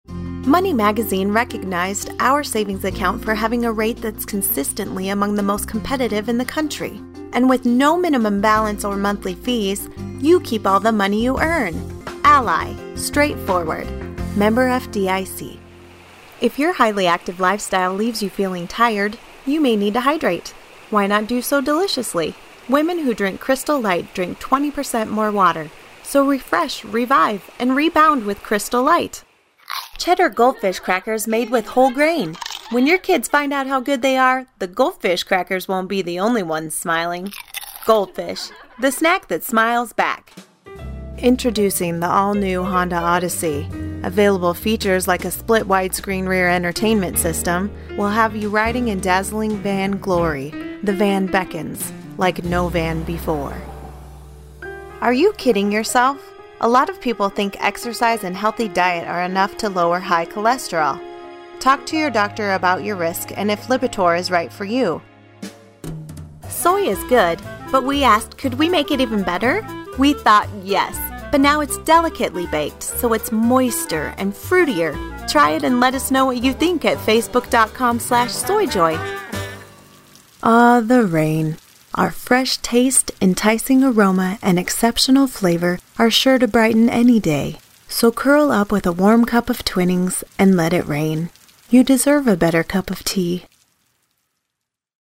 Gender: Female
VOICE OVER DEMO